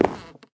sounds / step / wood6.ogg
wood6.ogg